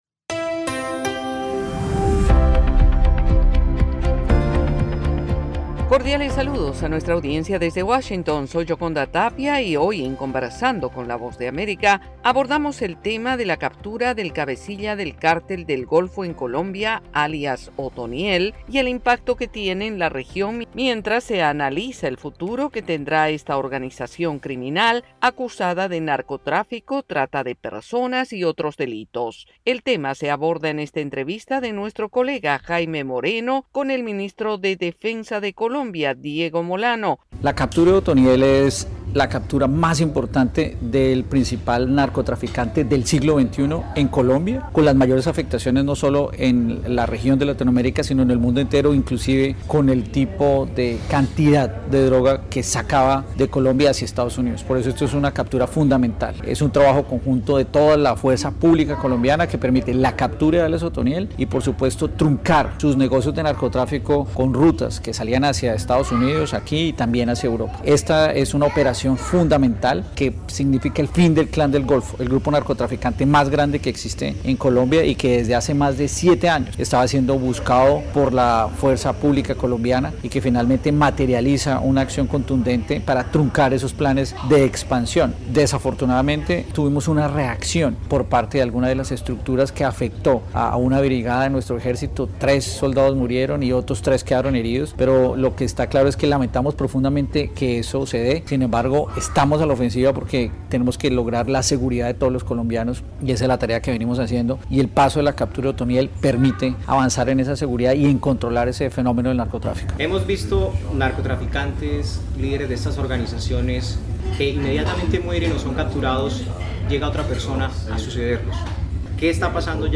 Conversamos con el ministro de Defensa de Colombia, Diego Molano, poniendo en contexto la posición del gobierno de su país en el marco de la captura de alias “Otoniel”, cabecilla del Cártel del Golfo.